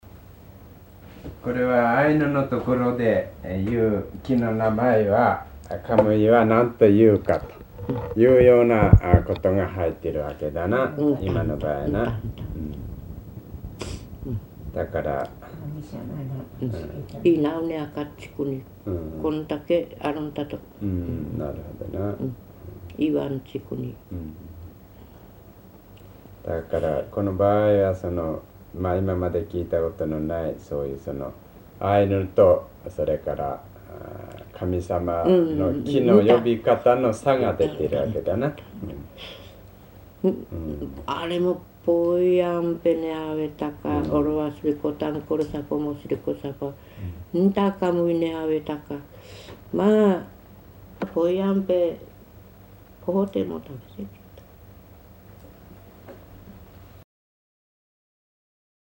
[14-5 解説 commentary] 日本語音声 0:58